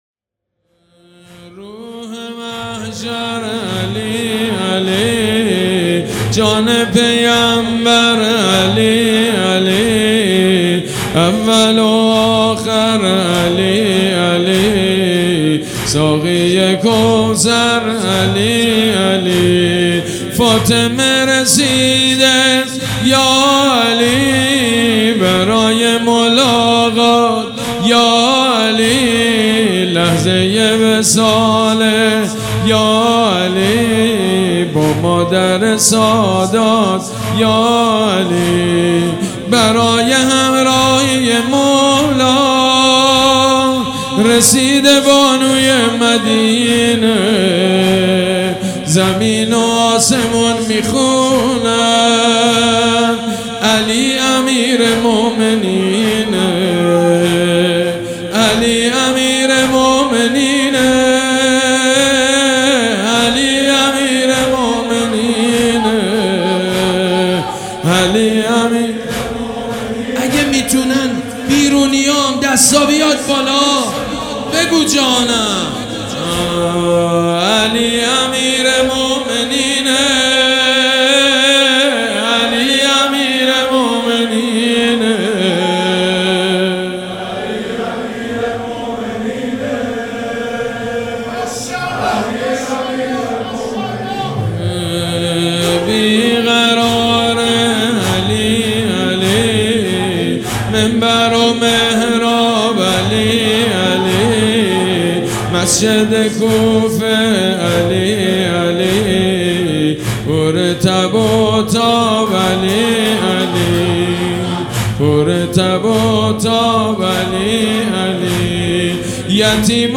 مداحی بنی‌فاطمه در شب قدر+ تواشیح: علی یا علی یا علی مولا
سیدمجید بنی‌فاطمه در شب احیاء هیأت ریحانةالحسین (س) نوحه‌ای برای امیرالمومنین (ع) خواند+ تواشیح: علی یا علی یا علی مولا